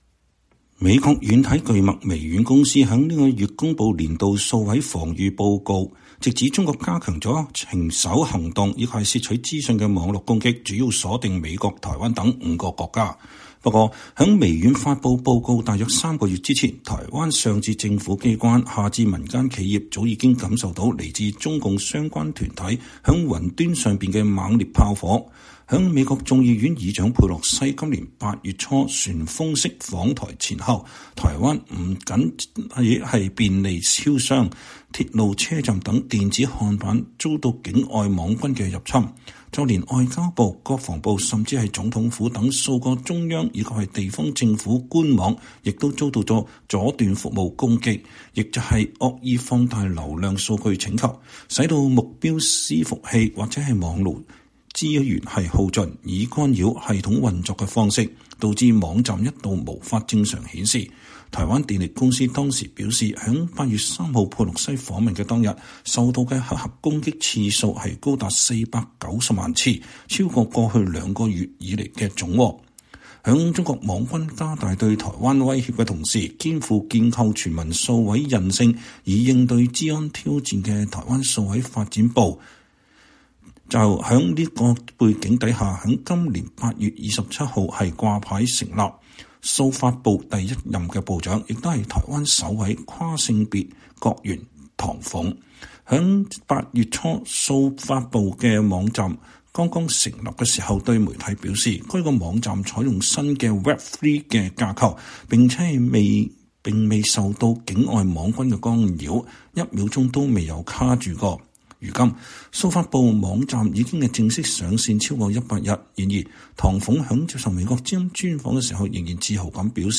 VOA專訪台灣數發部長唐鳳：佈建“零信任架構”、不斷網系統 擊退中共駭客及假消息攻擊